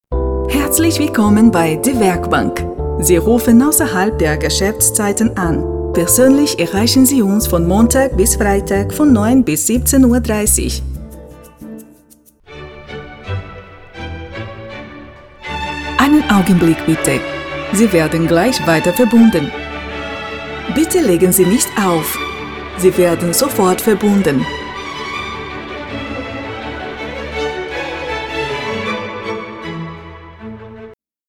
Female
30s, 40s, 50s, 60s
Microphone: Shure KSM 27
Audio equipment: sound proof recording both